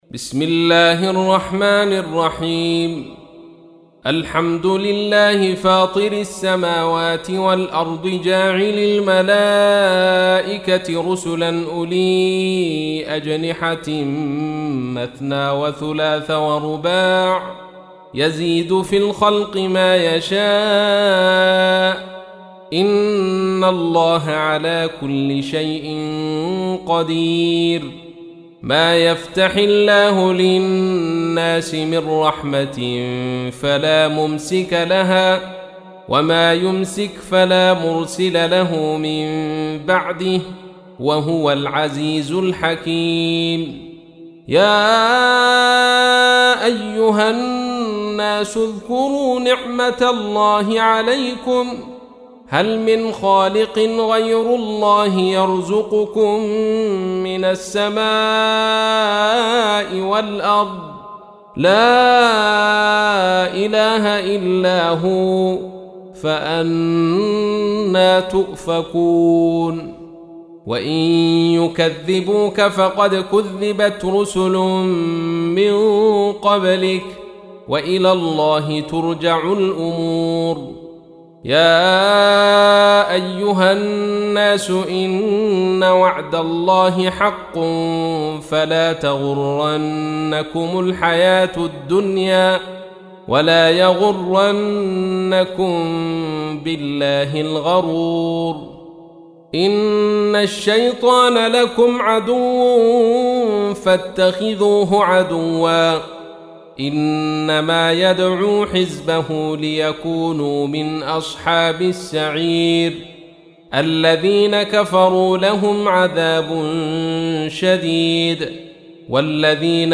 تحميل : 35. سورة فاطر / القارئ عبد الرشيد صوفي / القرآن الكريم / موقع يا حسين